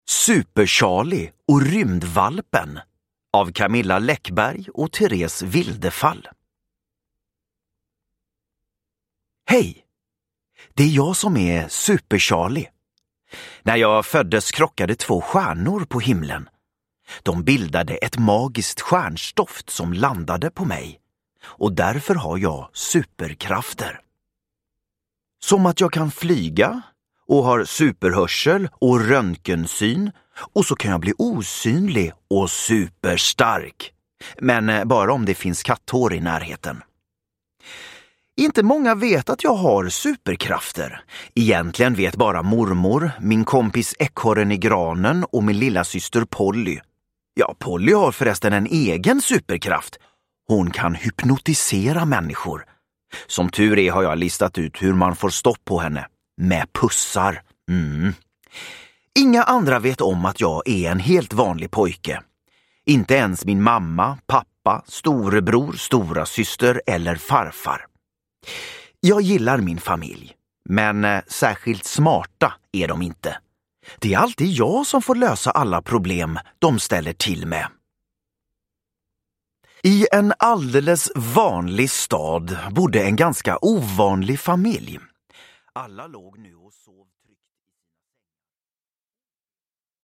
Super-Charlie och rymdvalpen – Ljudbok – Laddas ner
Uppläsare: Morgan Alling